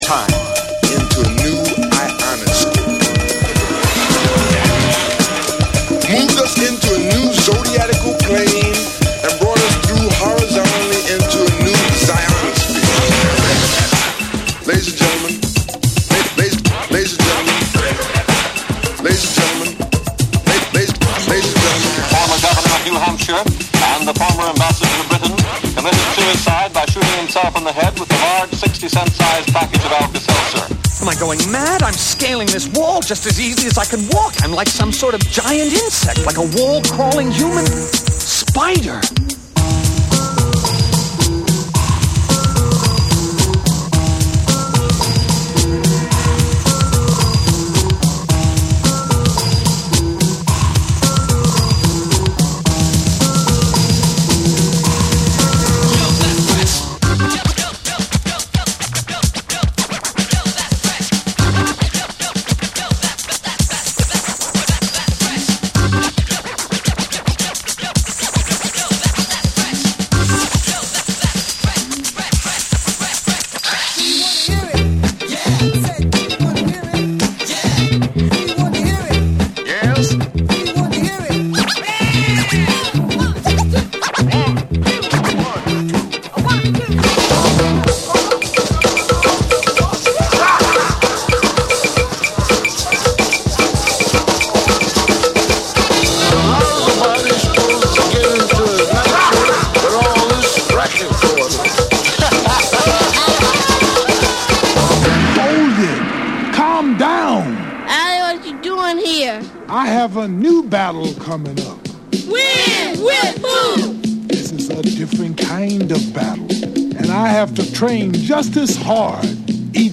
多彩な大ネタが次々と飛び出し、強烈な勢いで突き進むハイクオリティ・ブレイクビーツ。
BREAKBEATS